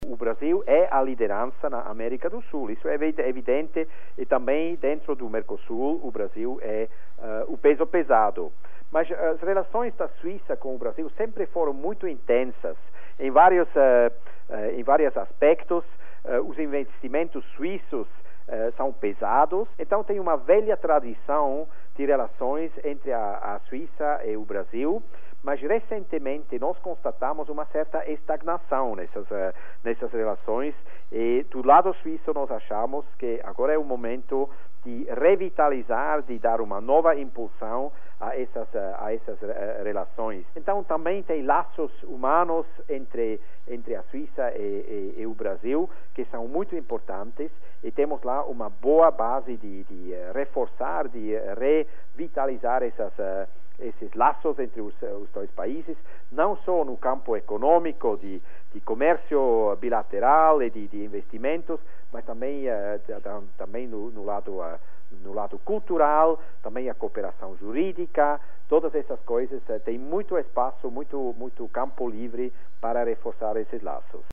O embaixador Rodolf Bärffus fala da vontade do governo suíço em incrementar os negócios com o Brasil.